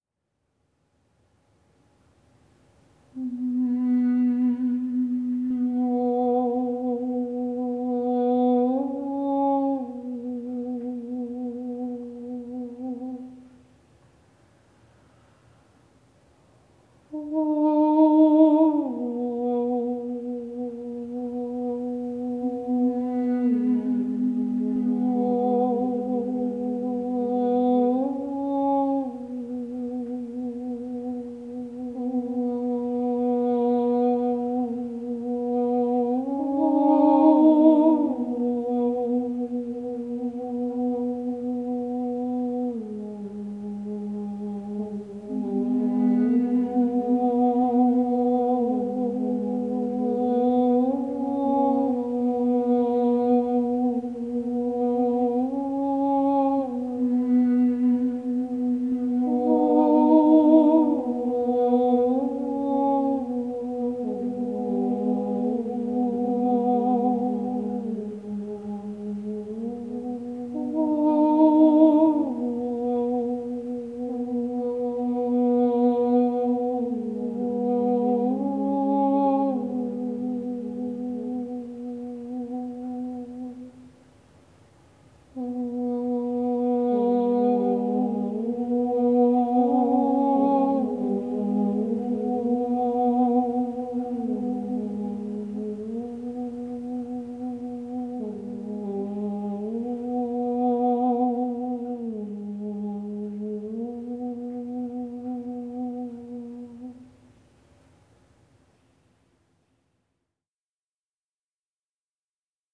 healing song